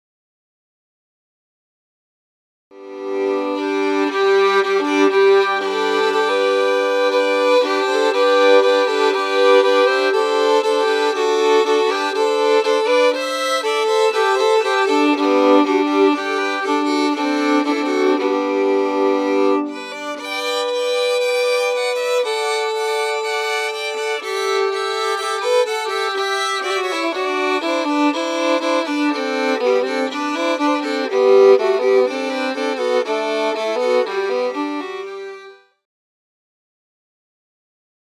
Tune #1(.mp3 file) is one that we often use for a bridal processional